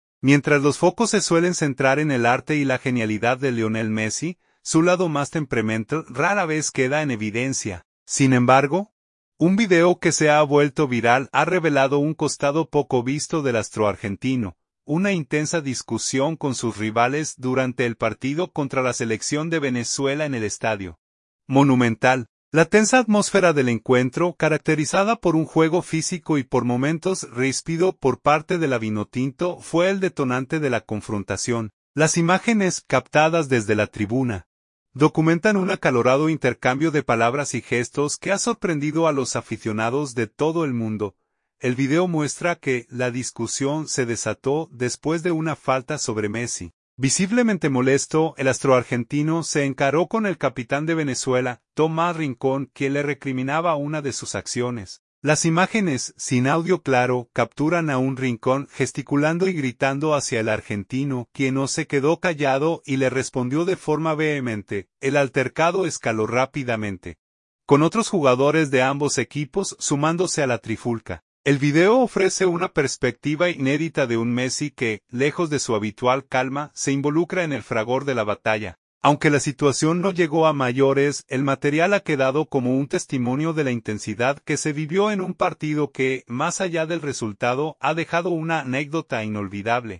Las imágenes, captadas desde la tribuna, documentan un acalorado intercambio de palabras y gestos que ha sorprendido a los aficionados de todo el mundo.
Las imágenes, sin audio claro, capturan a un Rincón gesticulando y gritando hacia el argentino, quien no se quedó callado y le respondió de forma vehemente.